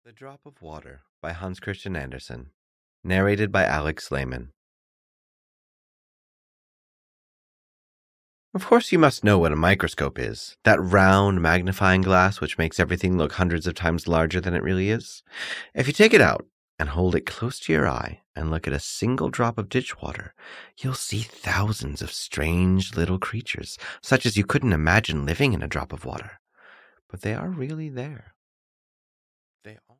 The Drop of Water (EN) audiokniha
Ukázka z knihy